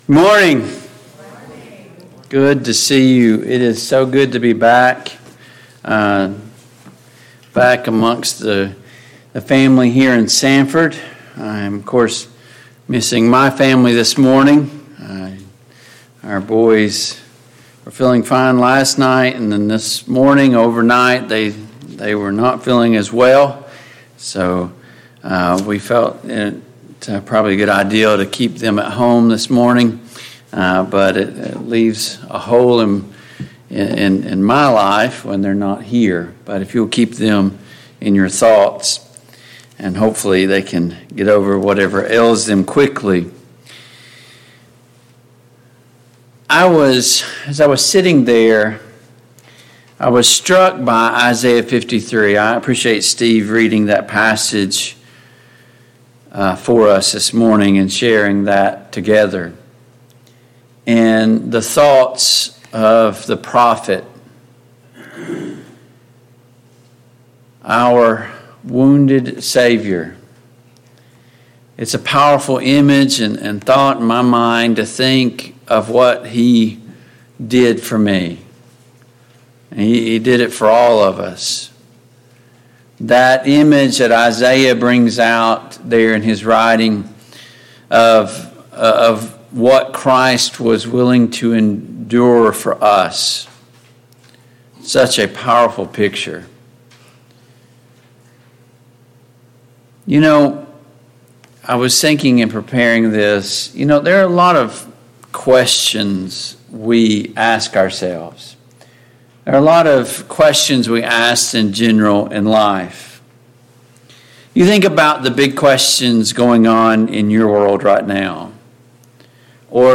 Passage: Hebrews 4:12-13 Service Type: AM Worship Download Files Notes Topics: Biblical Authority , truth « 7.